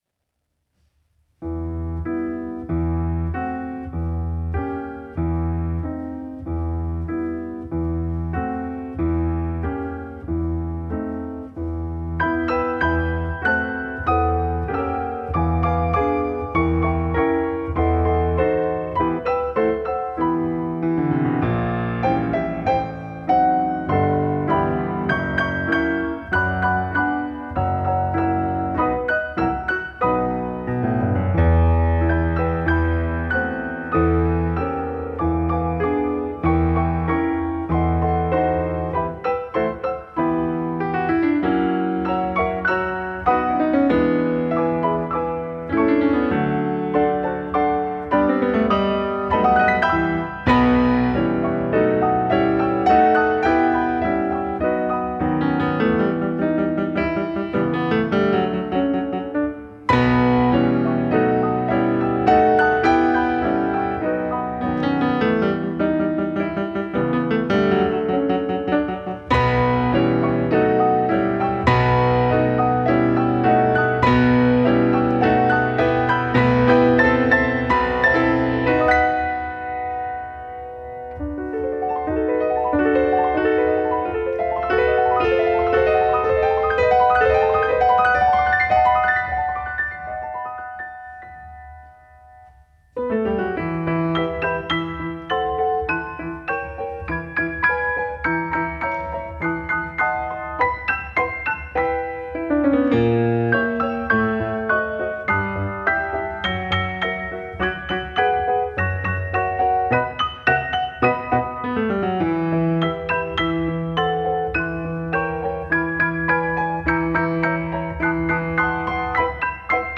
Two pianos
a live recording of a two piano recital
The combined sound of two concert grand pianos requires careful planning.